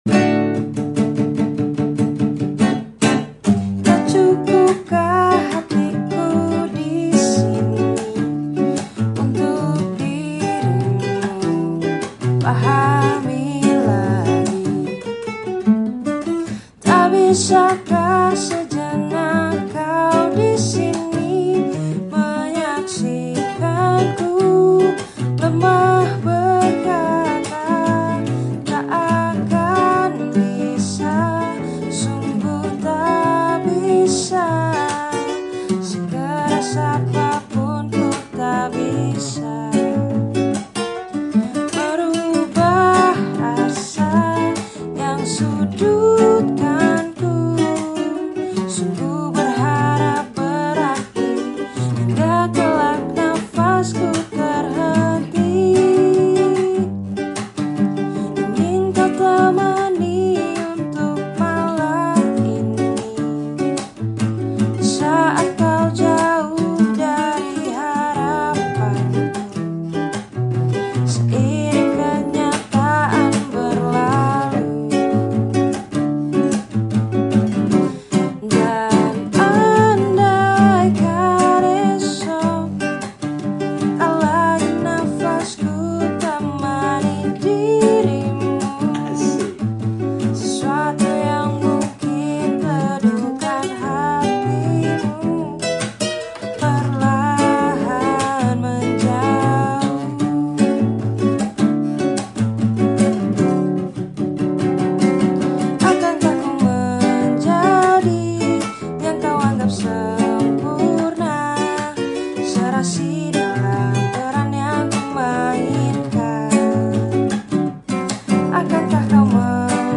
cover) Versi Ala-Ala Bossanova.
Gitar